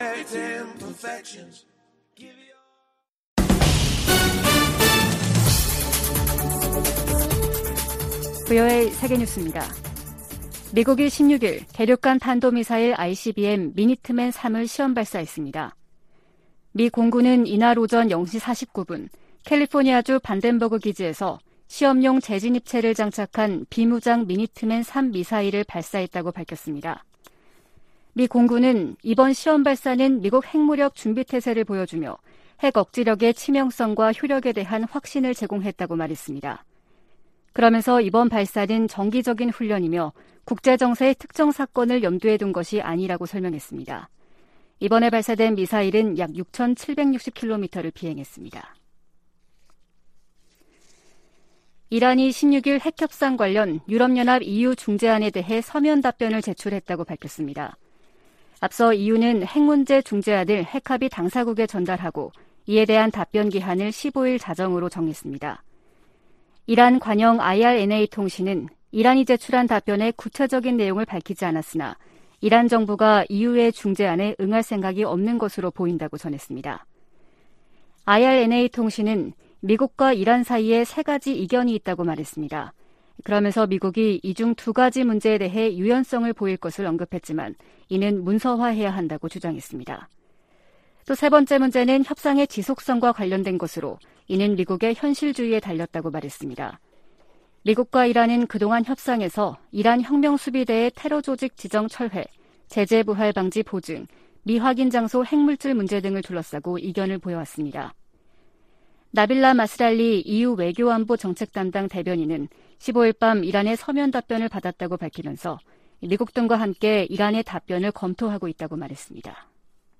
VOA 한국어 아침 뉴스 프로그램 '워싱턴 뉴스 광장' 2022년 8월 17일 방송입니다. 미국과 한국, 일본, 호주, 캐나다 해군이 하와이 해역에서 ‘퍼시픽 드래곤’ 훈련을 진행했습니다. 미 국무부는 한국 윤석열 대통령이 언급한 ‘담대한 구상’과 관련해 북한과 외교의 길을 모색하는 한국 정부를 강력히 지지한다고 밝혔습니다. 에드 마키 미 상원의원이 한국에서 윤석열 대통령과 권영세 통일부 장관을 만나 동맹 강화 방안과 북한 문제 등을 논의했습니다.